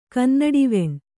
♪ kannaḍiveṇ